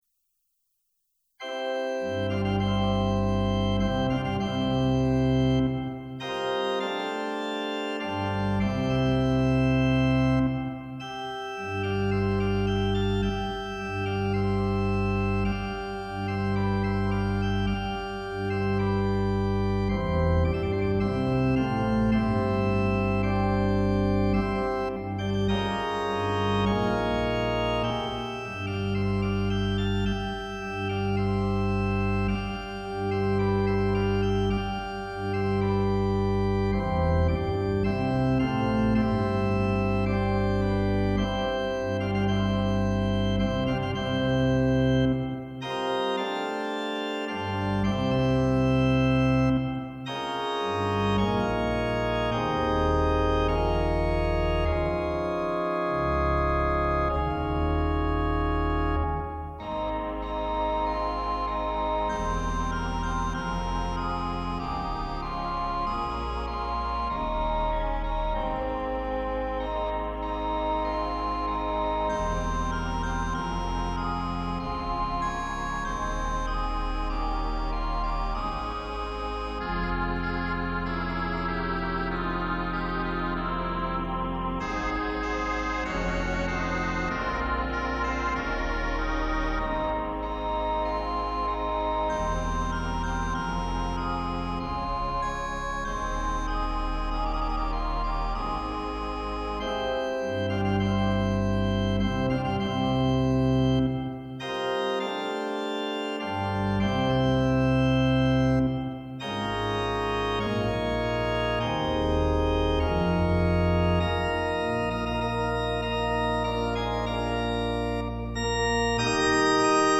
It is the best organ soundfont I've ever had.
Suite